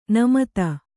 ♪ namata